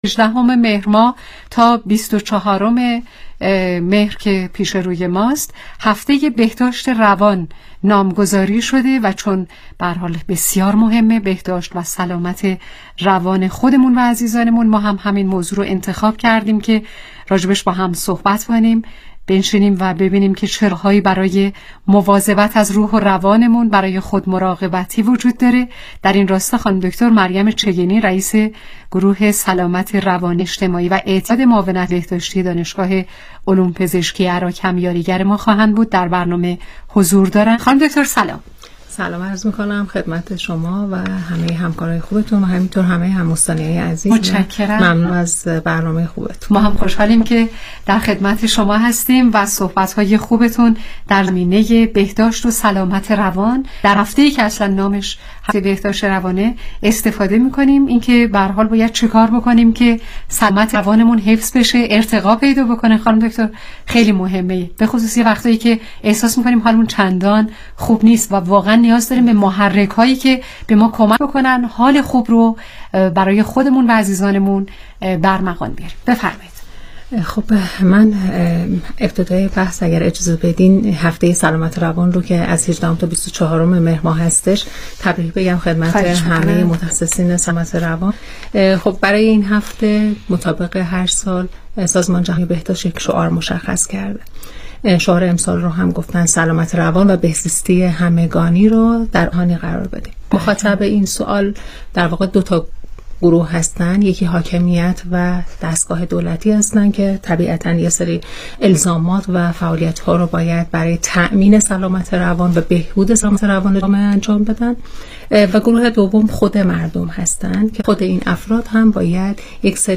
برنامه رادیویی کانون مهر&nbsp